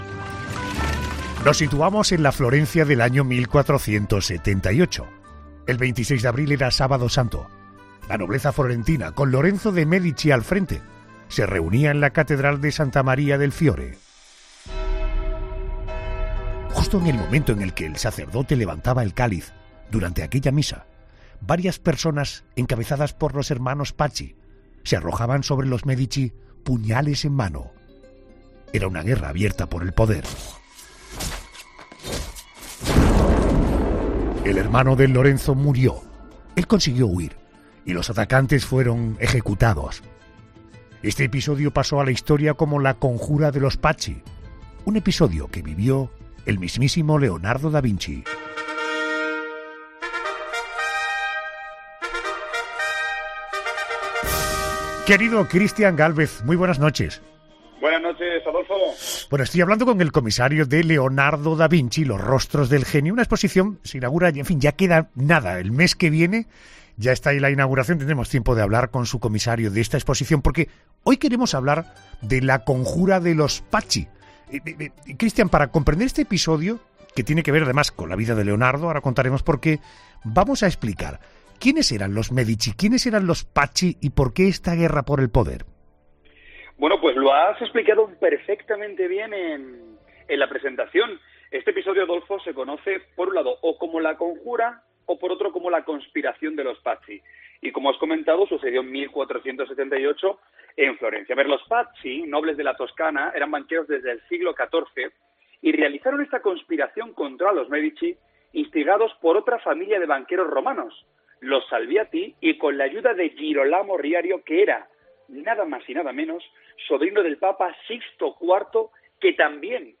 Christian Gálvez, experto en Leonardo da Vinci, repasa un lunes más en 'La Noche de COPE' un acontecimiento relevante en la historia del genio Leonardo